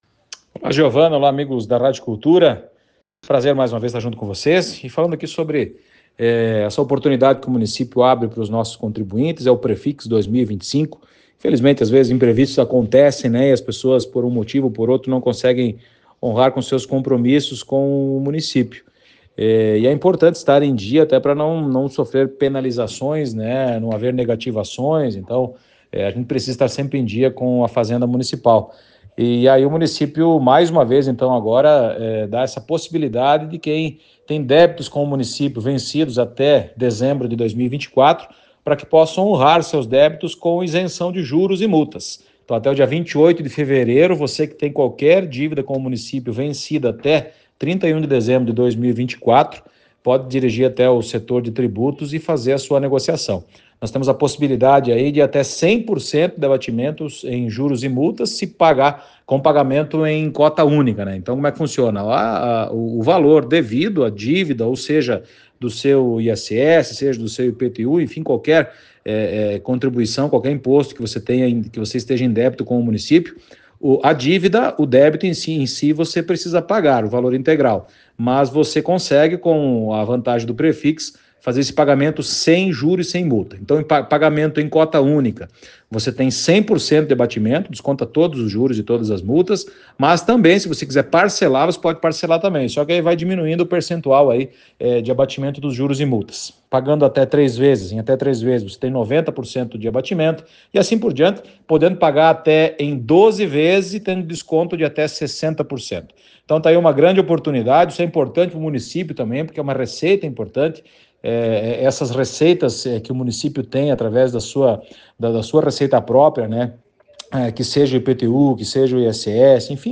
O Secretário de Administração Fazenda e Desenvolvimento Econômico Beto Grasel ressalta que é uma oportunidade do cidadão quitar alguma pendencia com o município em entrevista ao Conexão da Cultura FM.